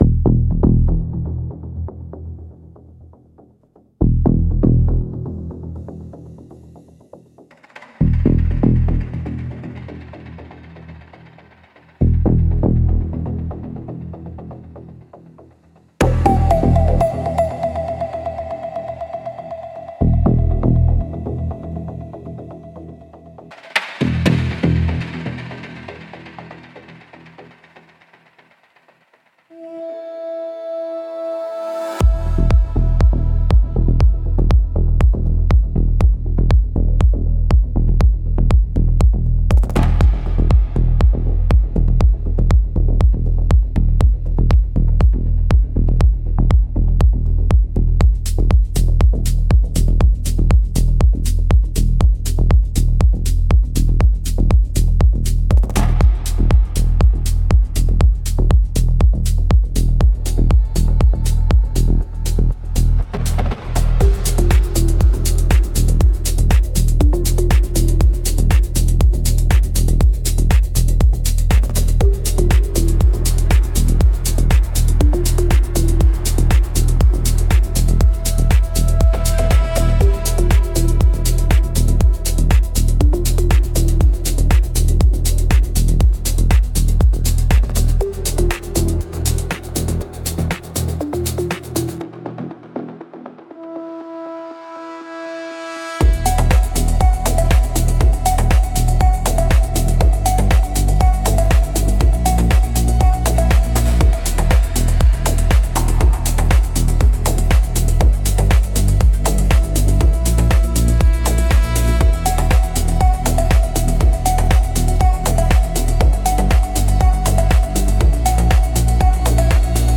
Instrumentals - Earth-Code Pulse